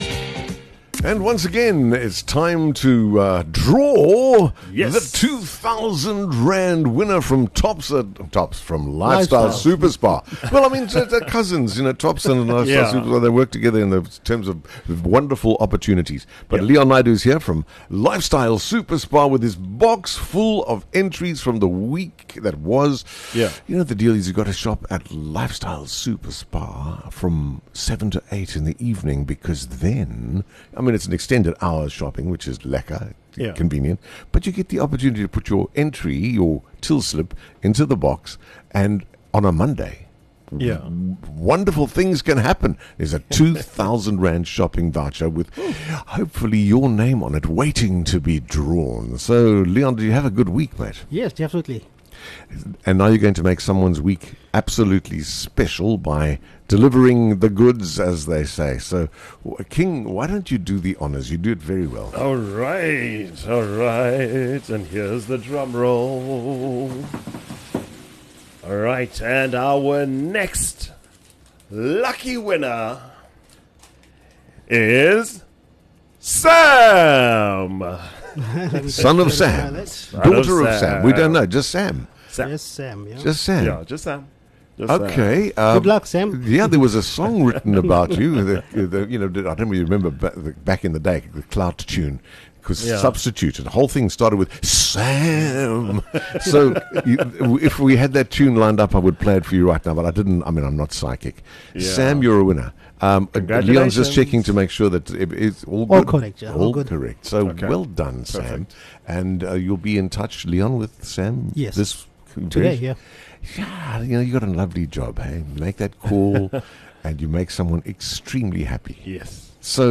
Arrive between 7:00 PM and 8:00 PM and stand a chance to enter and win a R2000 shopping voucher! Listen to our interview to learn how to enter and what is required.